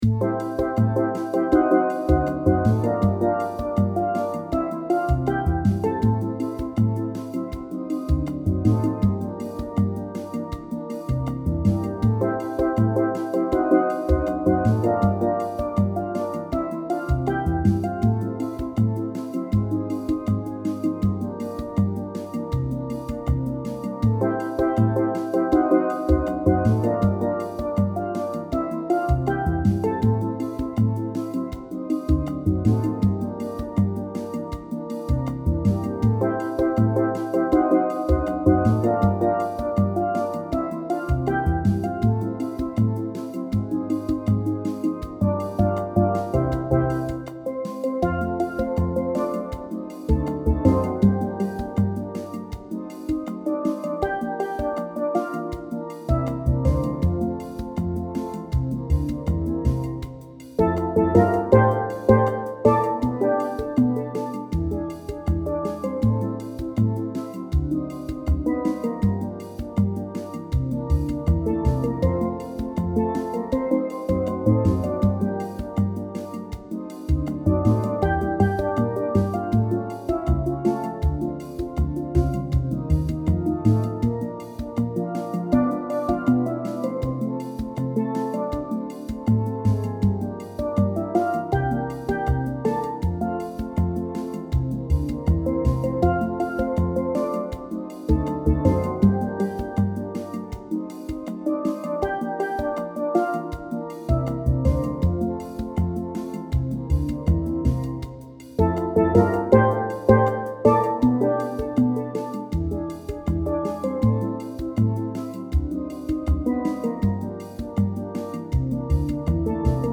Steel Band Sound Files
These mp3's are up to tempo.